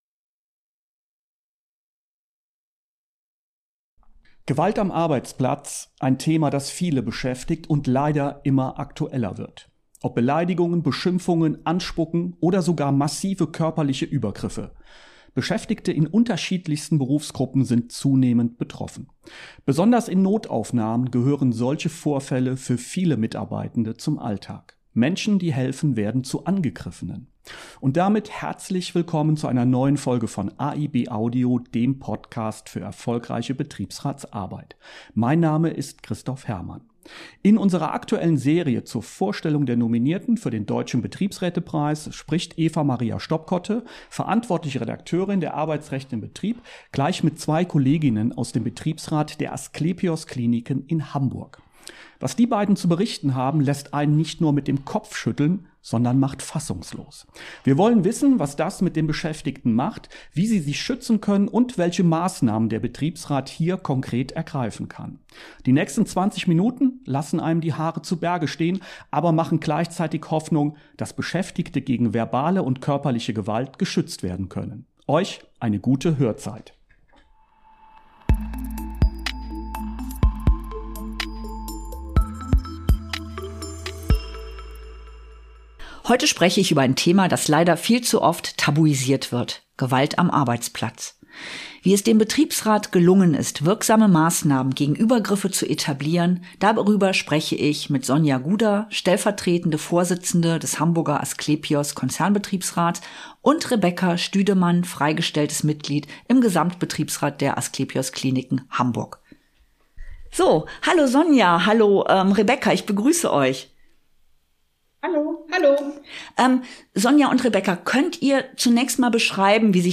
Sie berichten eindrücklich, was Gewalt im Arbeitsalltag bedeutet, wie sich Beschäftigte schützen können und welche konkreten Maßnahmen der Betriebsrat ergreift. Ein Gespräch, das betroffen macht – und zeigt, wie wichtig betriebliche Mitbestimmung ist.